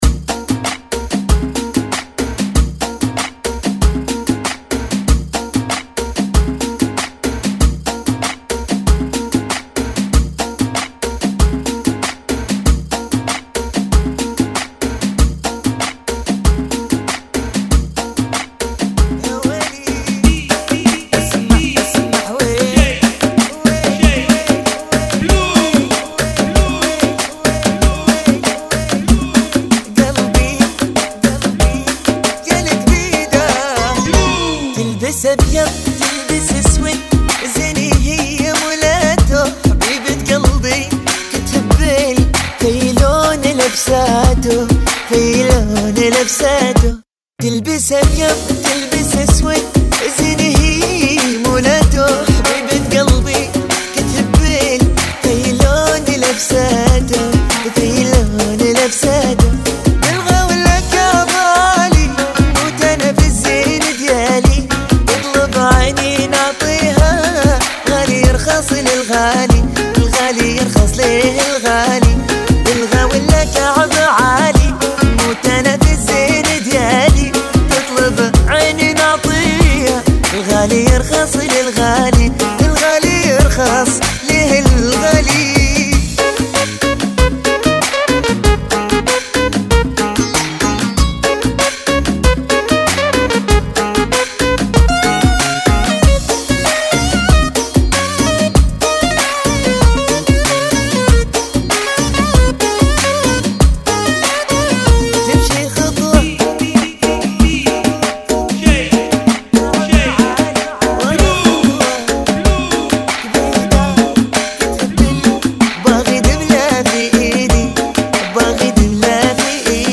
70 Bpm